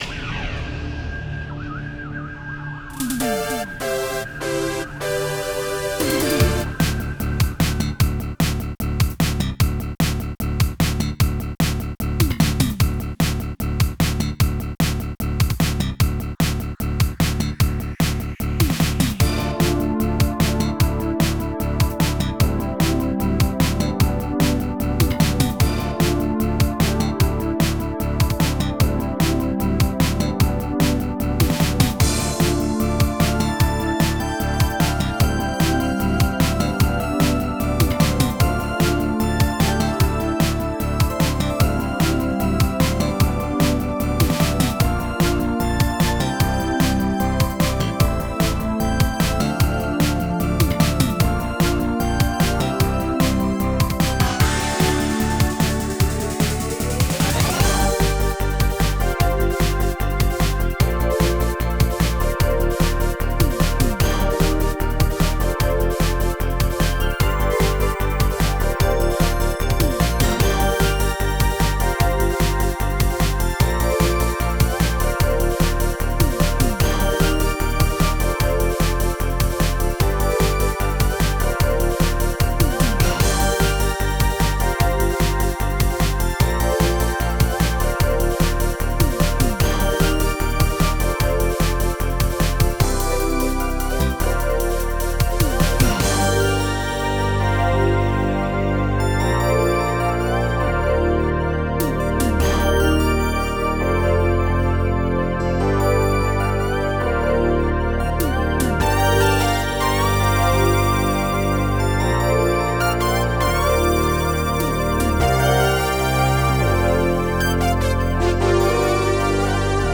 Style: Synthpop
ranked 5th in the tracked music compo
The pads and basses are also very 80s and very nice.